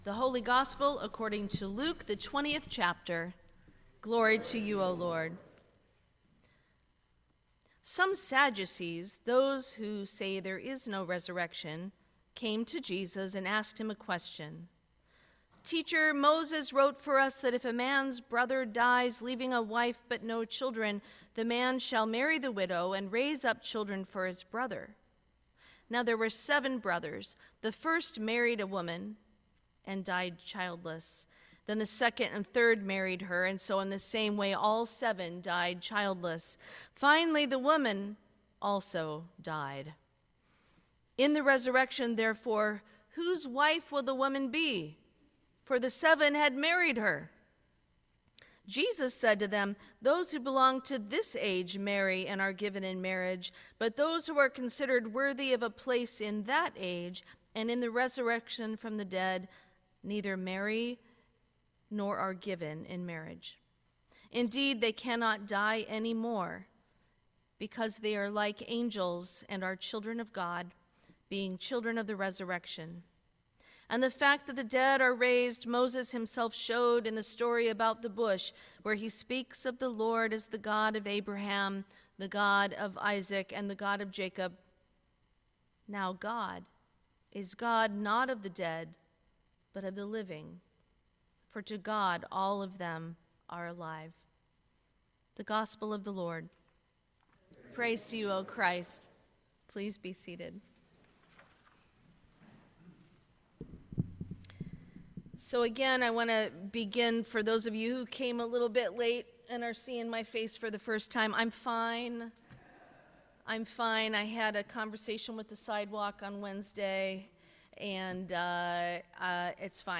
Sermon for the Twenty-Second Sunday after Pentecost 2025